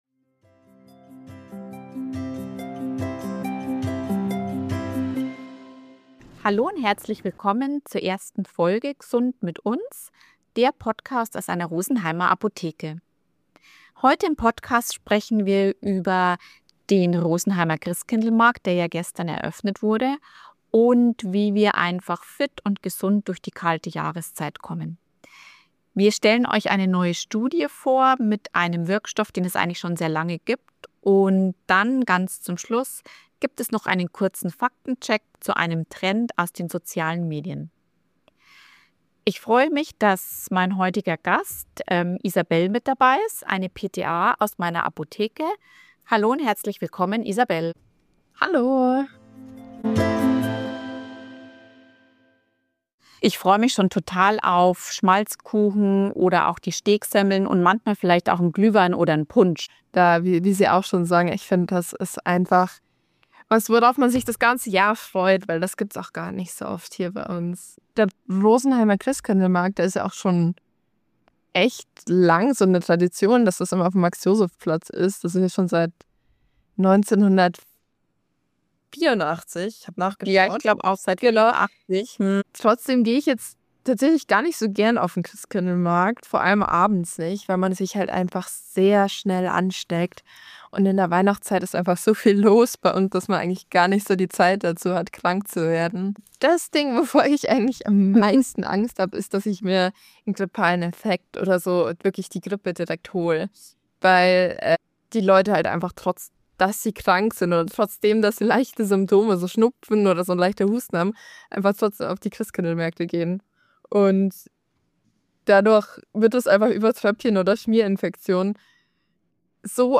Heute spreche ich mit einer PTA aus meiner Apotheke darüber, wie du die Adventszeit aufm Christkindlmarkt genießen kannst, ohne krank nach Hause zu kommen.